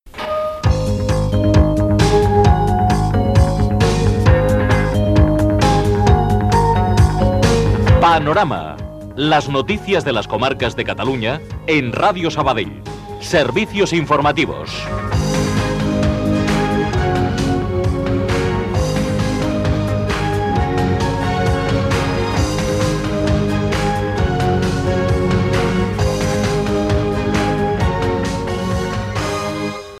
Careta del programa
Informatiu